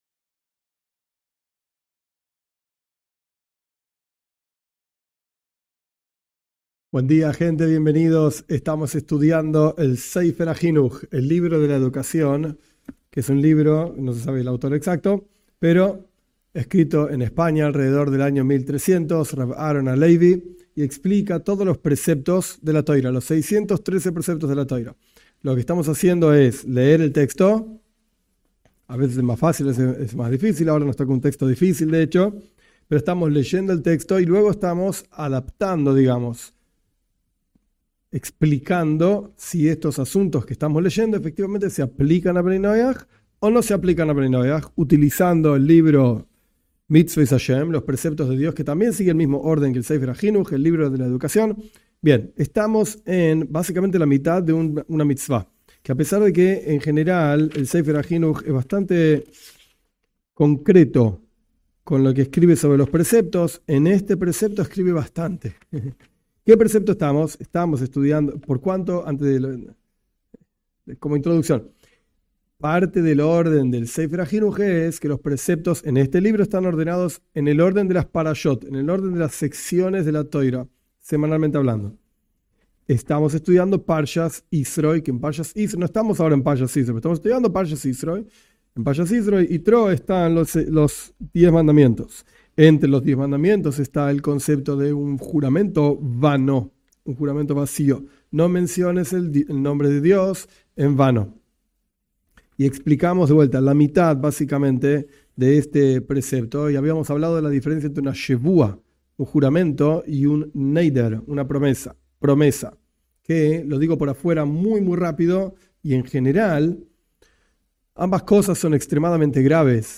En este curso estudiaremos los preceptos del judaísmo en forma breve, basándose en el libro de la educación (Sefer HaJinuj) y aplicándolos a Bnei Noaj de acuerdo a los escritos de Rabí Ionatan Steiff (1877-1958). En esta clase estudiamos los preceptos relacionados a los Diez Mandamientos.